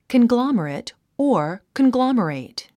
発音 kənglɑ’mərət カングロォマレェト